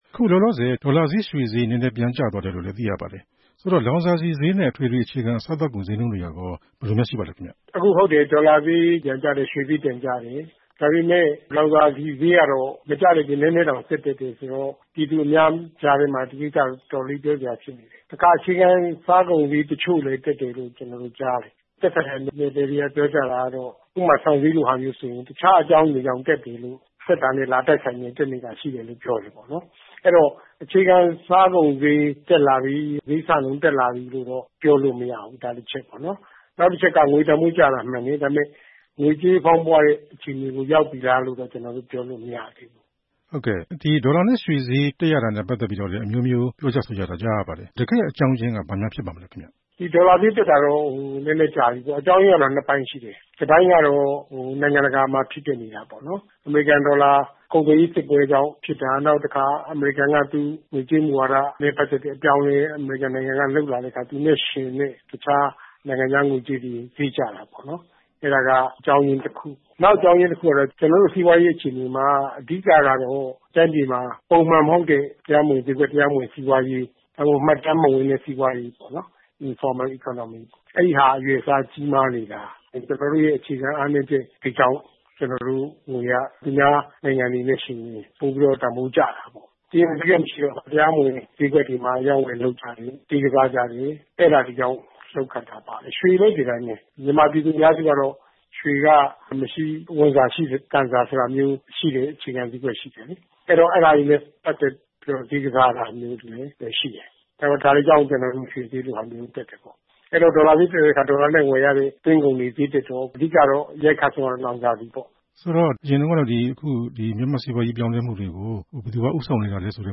မြန်မာ့စီးပွားရေးအလားအလာ ဆက်သွယ်မေးမြန်းချက်